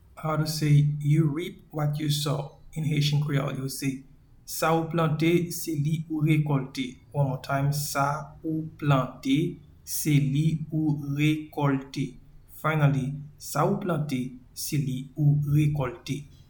Pronunciation and Transcript:
You-reap-what-you-sow-in-Haitian-Creole-Sa-ou-plante-se-li-ou-rekolte.mp3